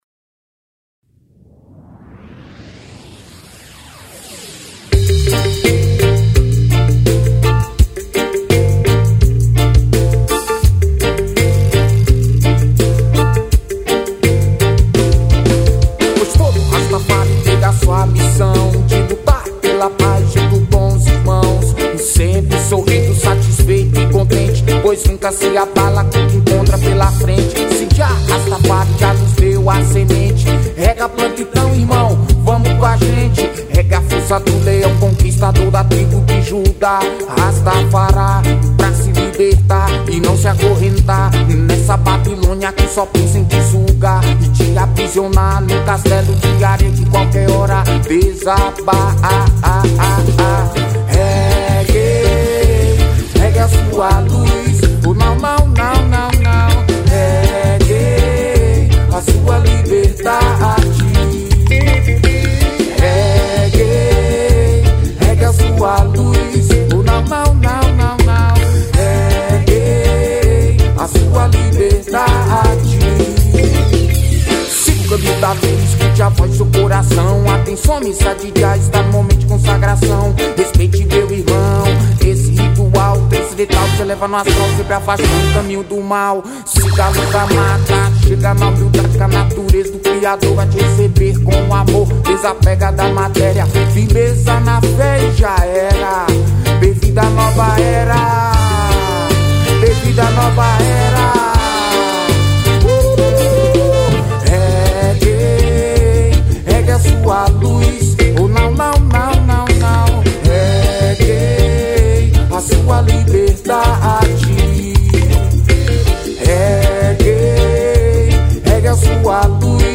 2229   03:53:00   Faixa:     Reggae